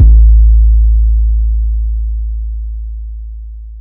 OZ - 808 4.wav